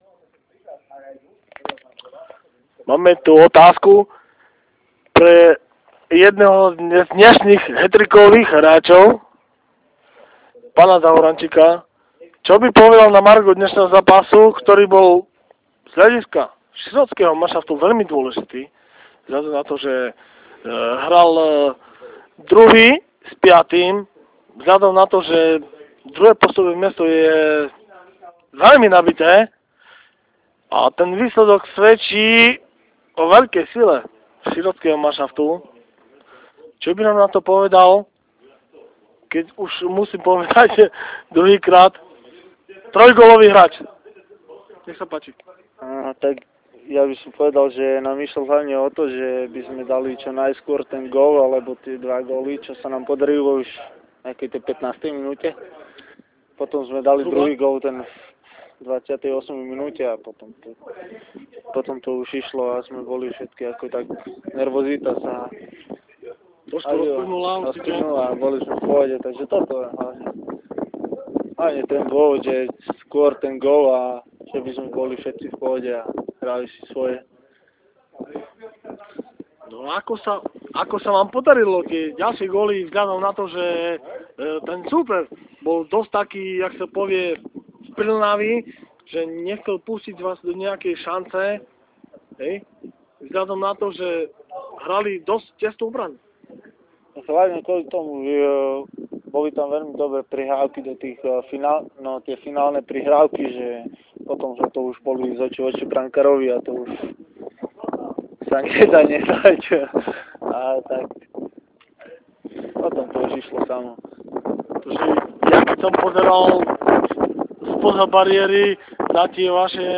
ROZHOVORY